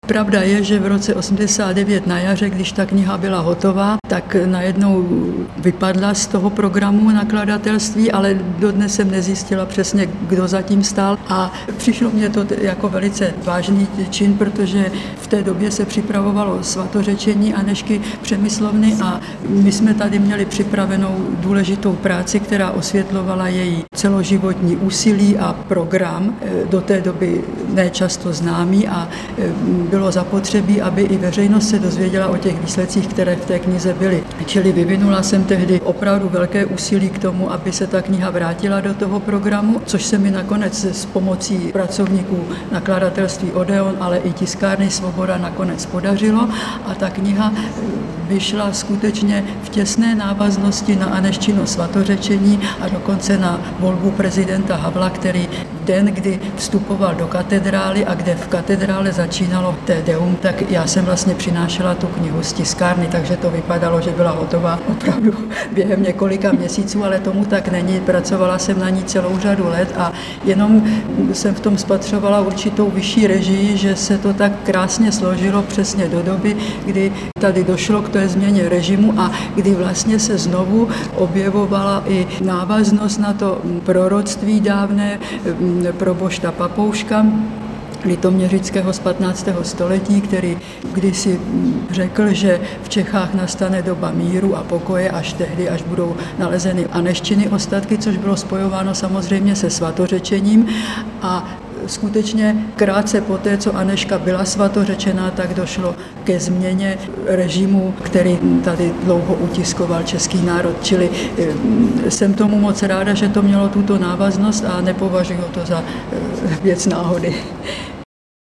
V pražském vydání Magdazínu uvádíme k zahájení Roku Anežky České reportáž z kláštera, který královská dcera z přemyslovského rodu založila pravděpodobně v roce 1231 na pravém břehu řeky Vltavy.